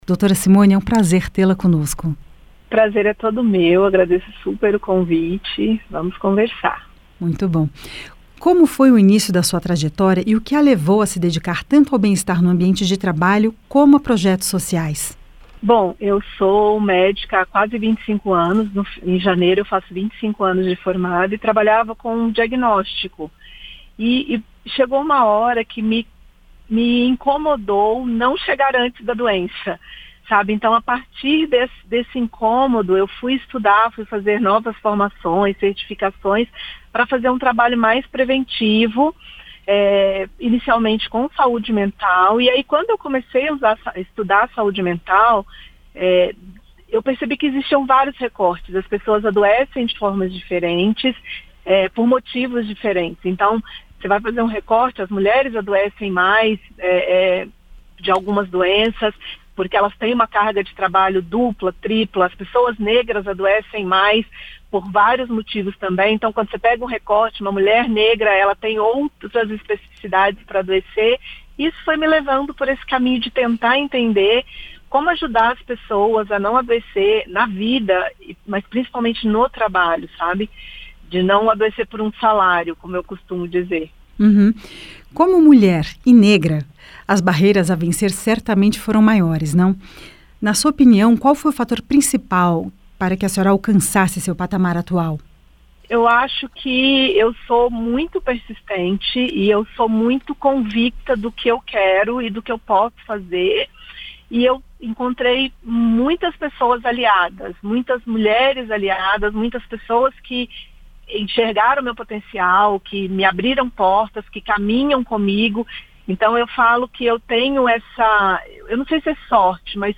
* Entrevista originalmente veiculada em 28/11/2024.